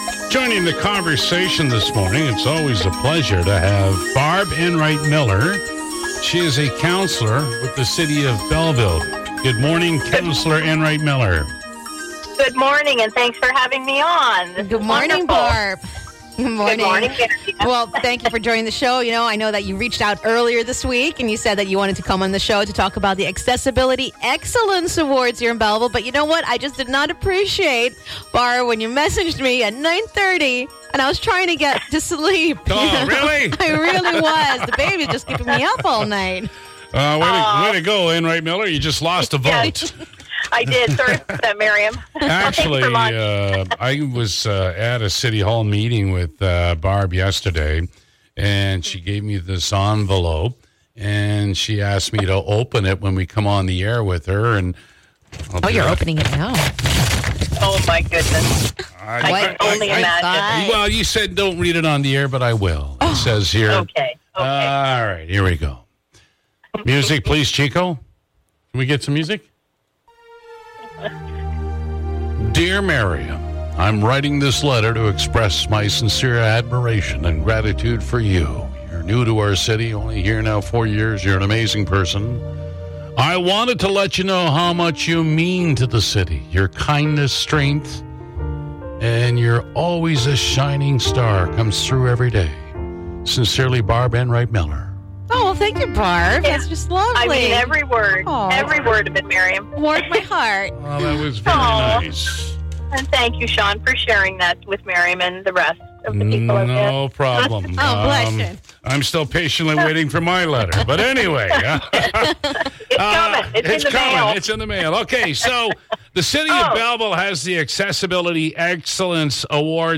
Councillor Barb Enright-Miller joins the MIX 97 conversation: Accessibility Excellence Awards are coming up & nominations are now open